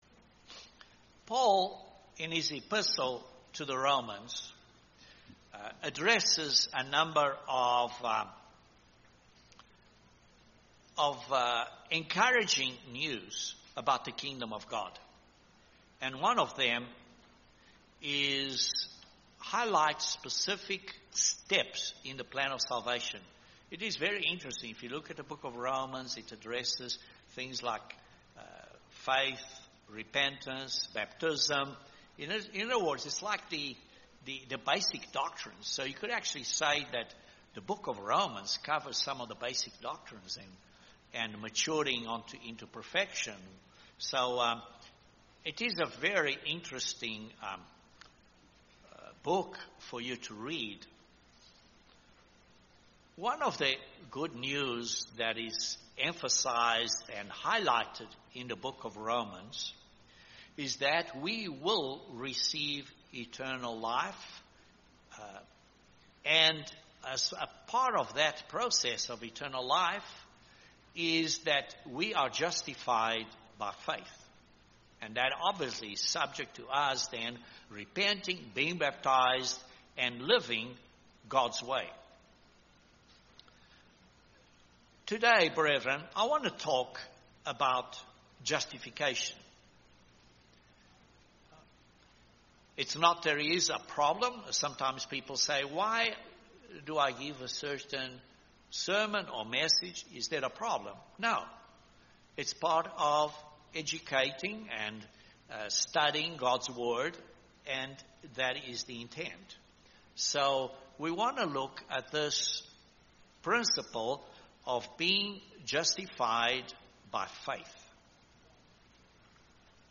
Justification by faith is an often misunderstood topic. This sermon explains this theme from Paul’s epistle to the Romans.